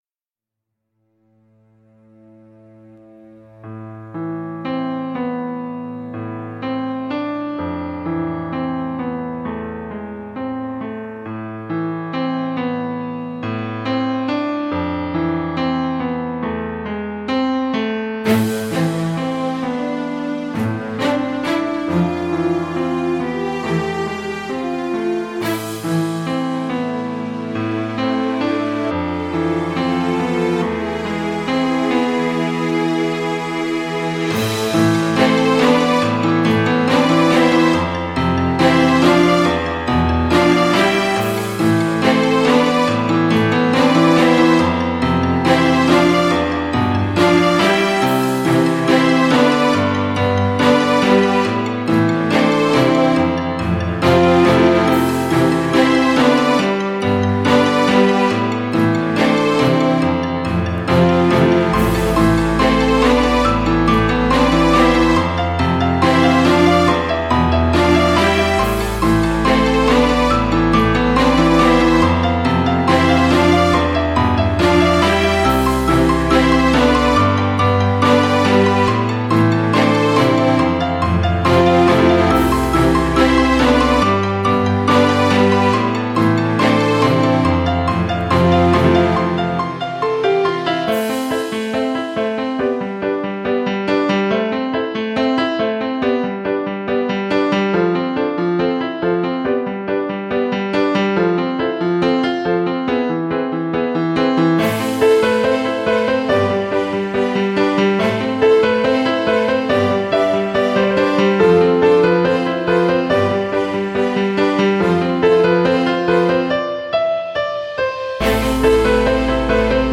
Žánr: Jazz/Blues
Trošku symfonický a orchestrálny experiment.